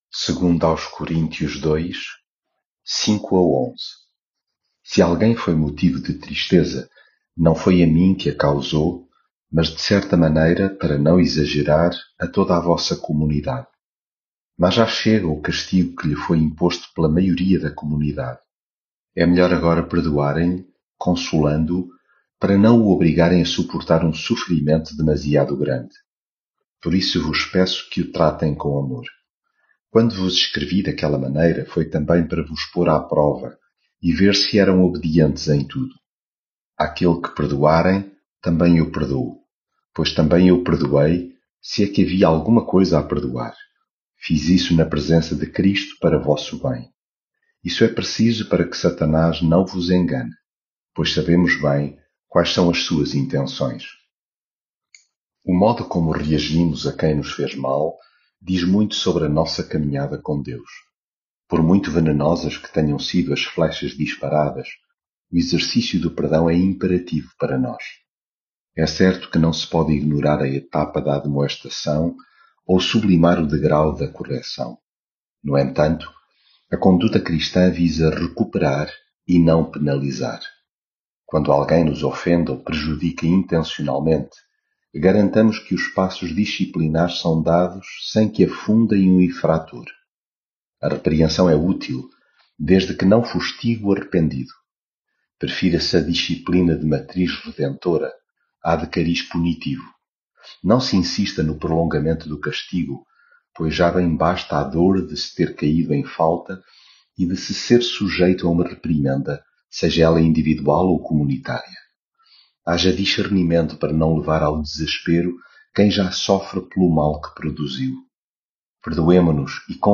devocional coríntios leitura bíblica Se alguém foi motivo de tristeza, não foi a mim que a causou mas, de certa maneira, para não exagerar, a...